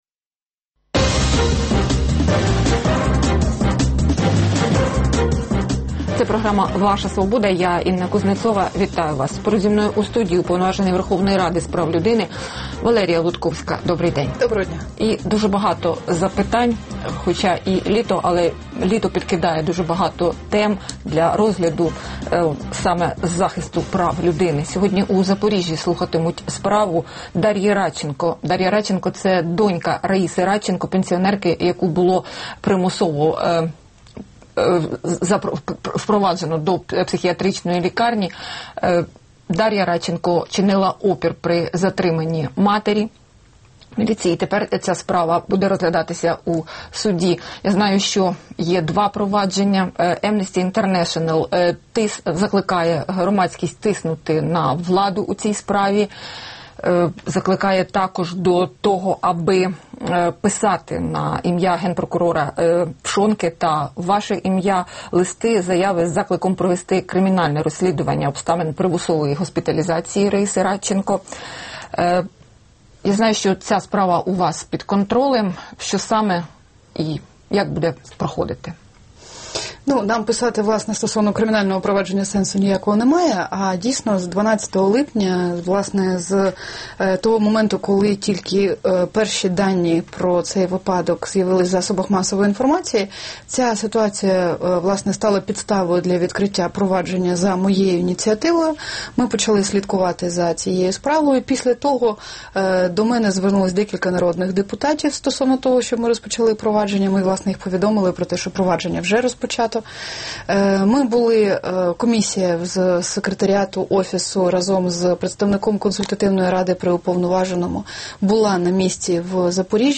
Гість: Валерія Лутковська, уповноважений Верховної Ради з прав людини